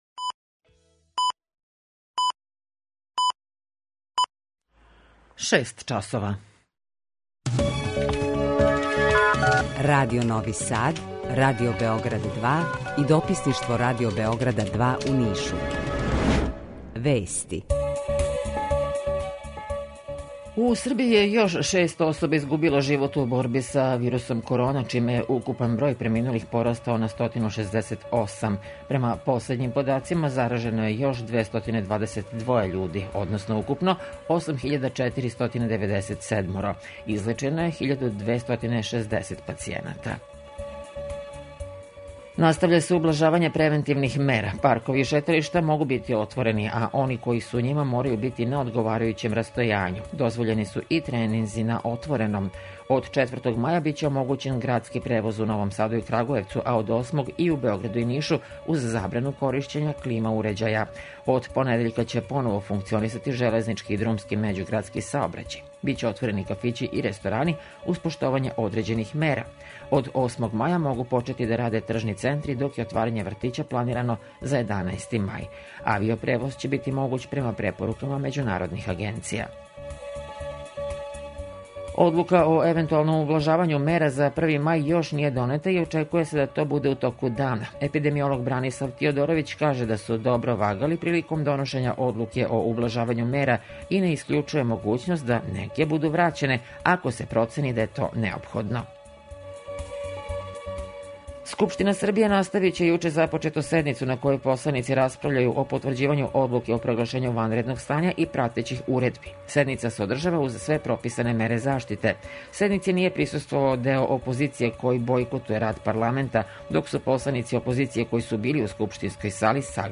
Укључење Радио Грачанице
Јутарњи програм из три студија